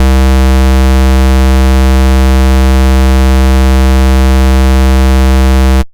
2600 Square.wav